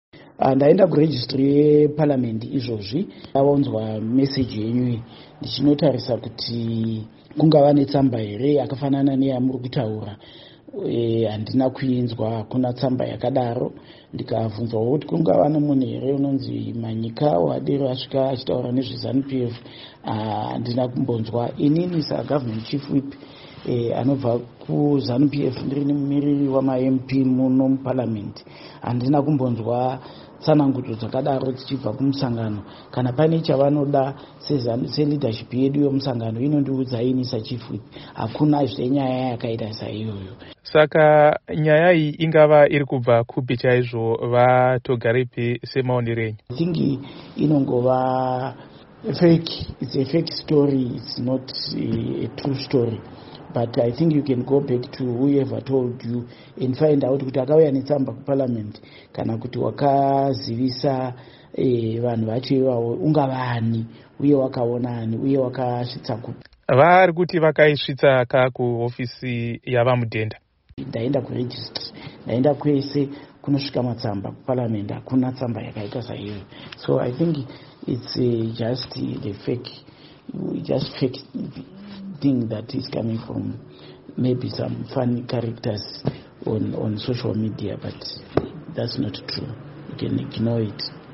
Hurukuro naVaPupurai Togarepi